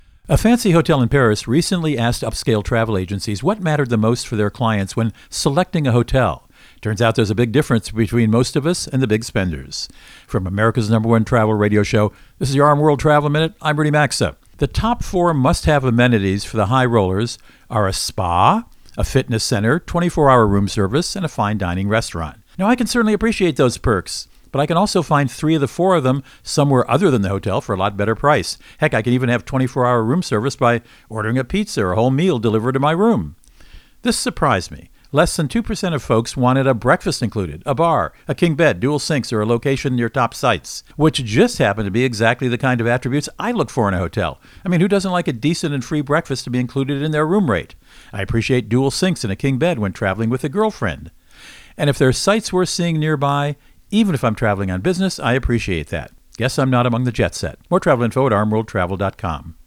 Co-Host Rudy Maxa | Hotel Guest Wants